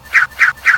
get_nunchucks.ogg